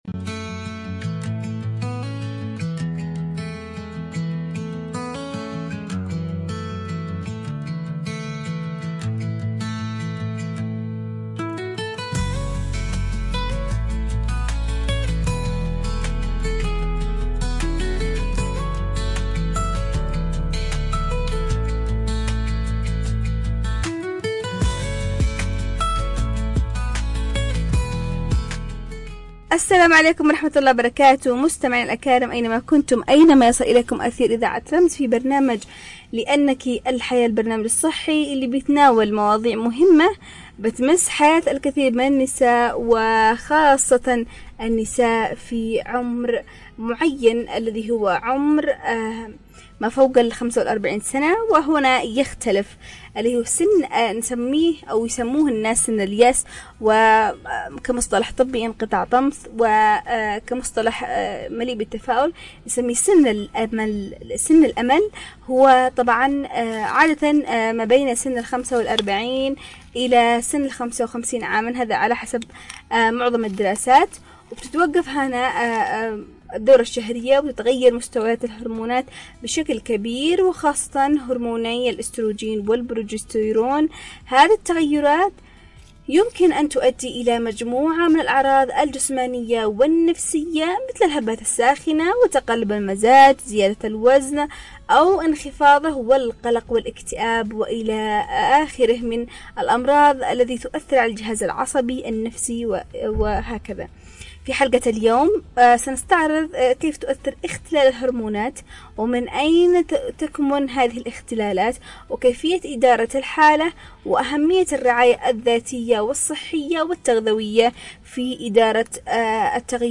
عبر أثير إذاعة رمز